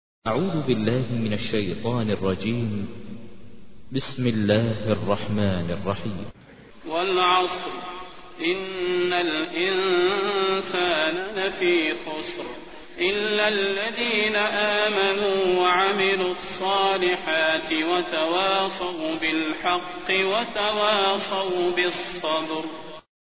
تحميل : 103. سورة العصر / القارئ ماهر المعيقلي / القرآن الكريم / موقع يا حسين